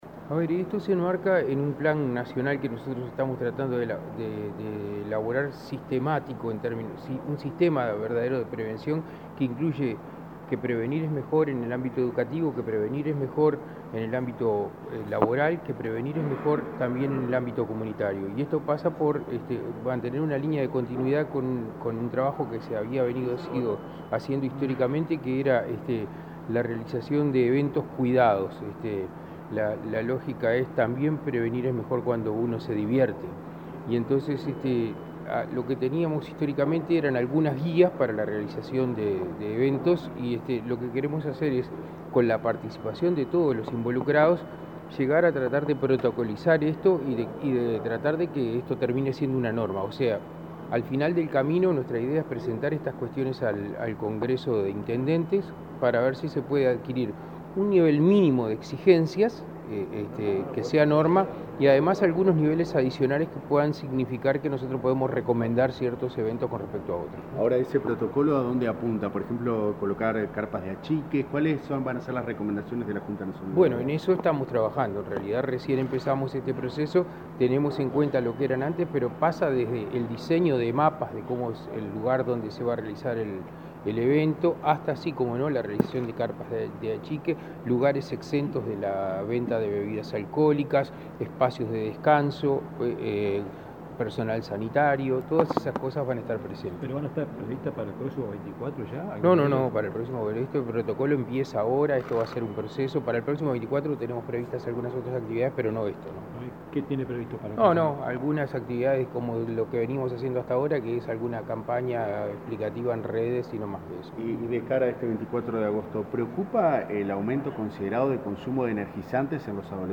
Declaraciones a la prensa del director de la JND, Daniel Radío
Tras reunirse con responsables de celebraciones, este 16 de agosto, el secretario general de esa dependencia, Daniel Radío, explicó a la prensa la iniciativa.